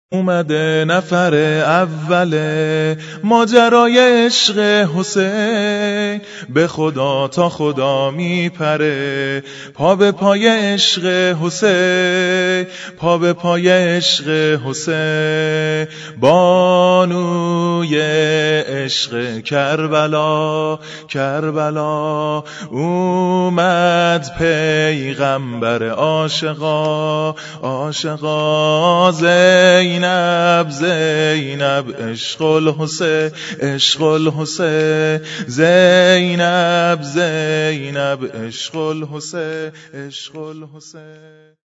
-سرود میلاد-